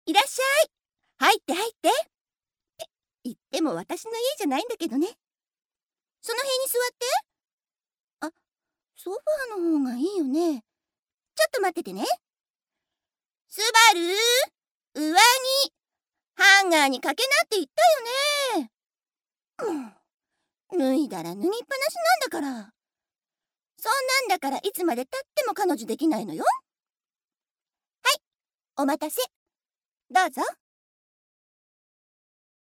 アトリエピーチのサンプルボイス一覧および紹介